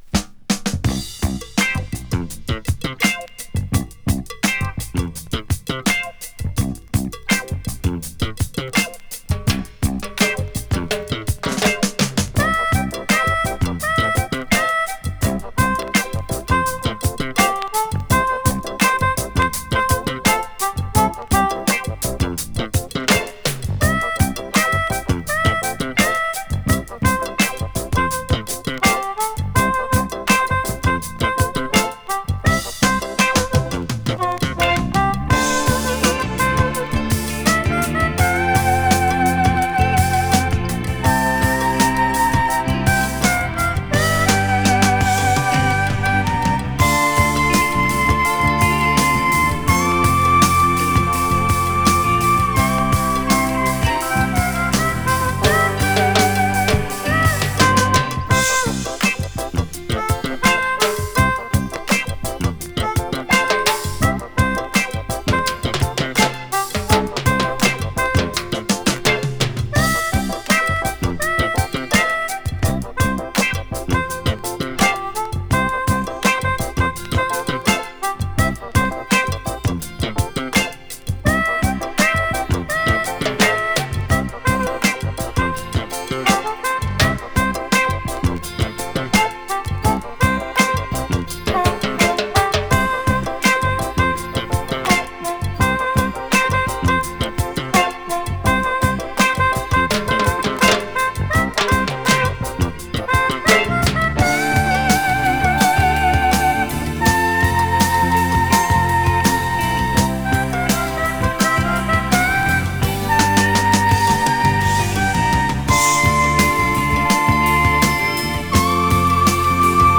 WARの唯一の白人メンバーであるハーモニカ奏者
グルーヴィーな演奏とハーモニカのメロディーに酔いしれる傑作!!